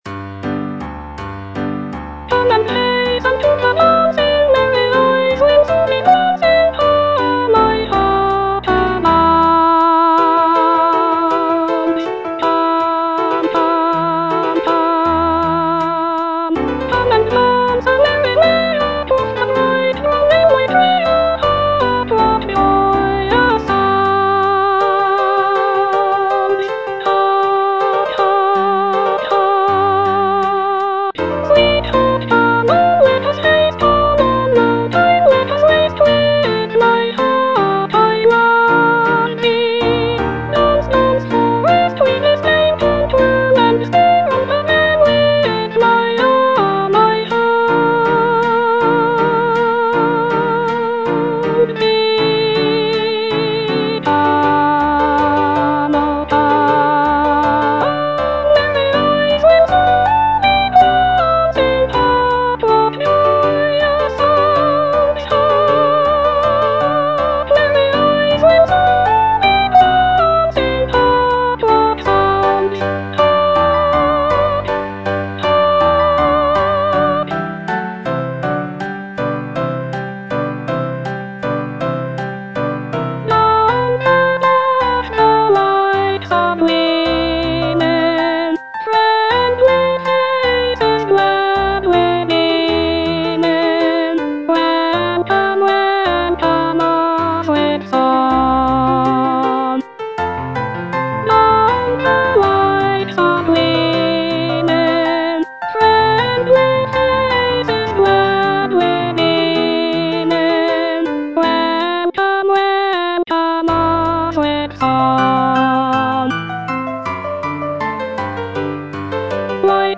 E. ELGAR - FROM THE BAVARIAN HIGHLANDS The dance (soprano I) (Voice with metronome) Ads stop: auto-stop